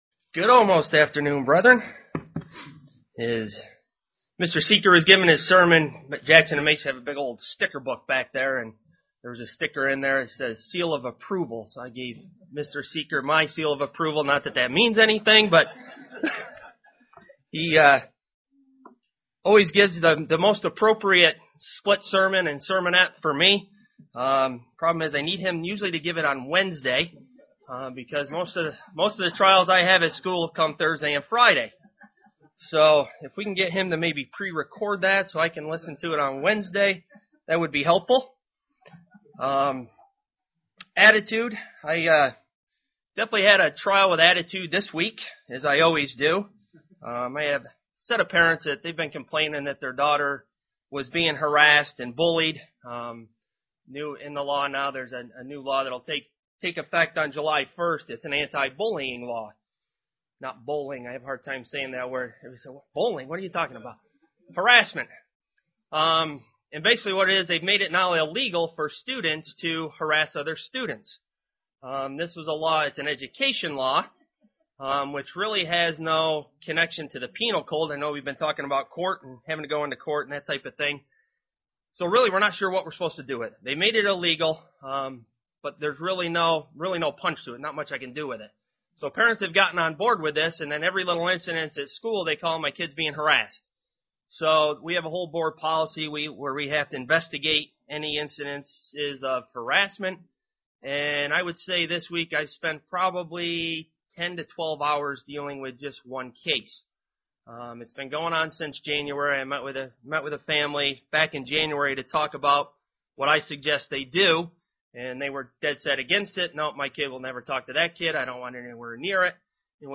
Print Explanation of some of the Covenants of God UCG Sermon Studying the bible?
Given in Elmira, NY